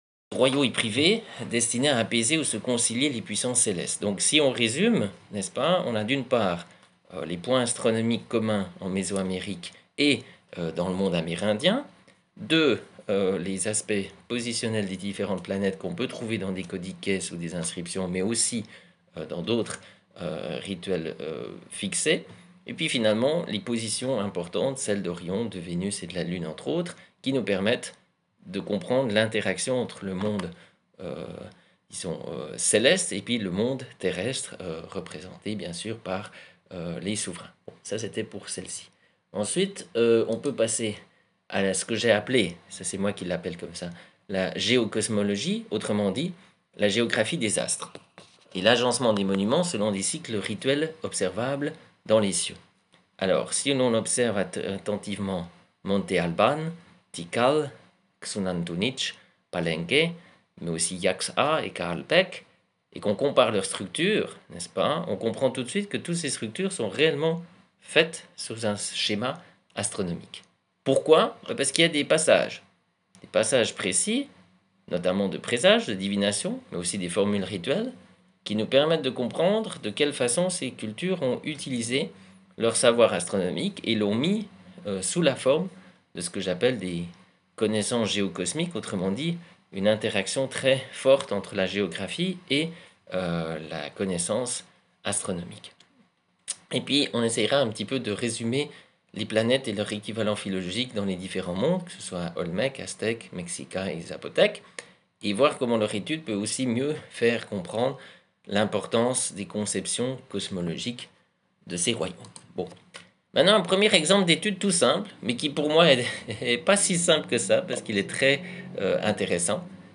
conférence sur les Calendriers de l'ancienne Amérique centrale